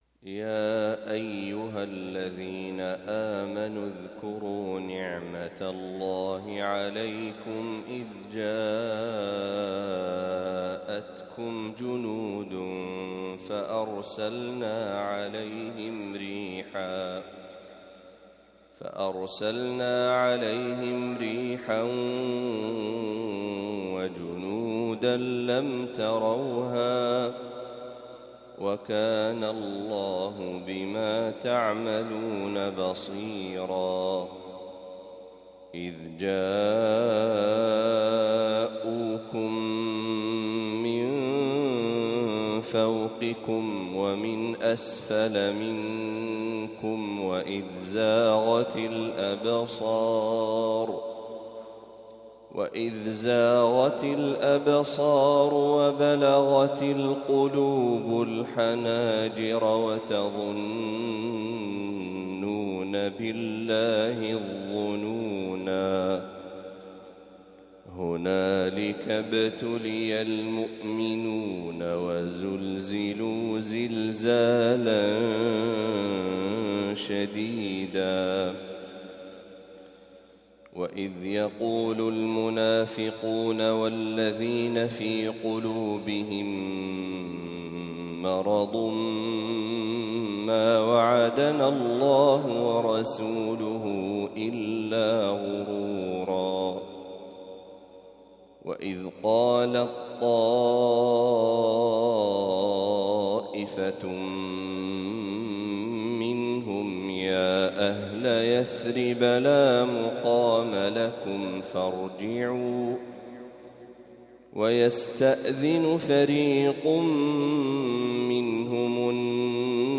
ما تيسر من سورة الأحزاب | فجر الاثنين ٢٢ صفر ١٤٤٦هـ > 1446هـ > تلاوات الشيخ محمد برهجي > المزيد - تلاوات الحرمين